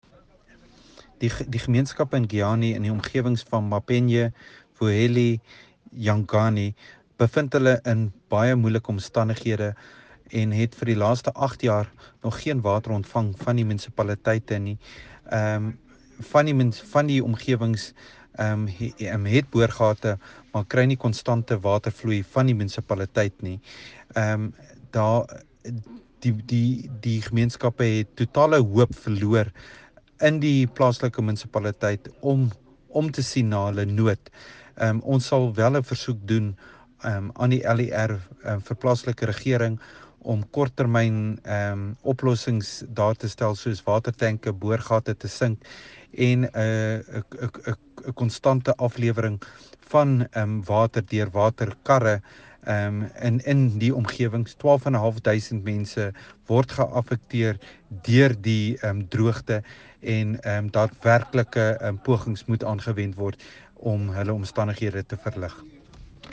here for an Afrikaans soundbite by Jacques Smalle and here for an English soundbite by Phumzile Van Damme.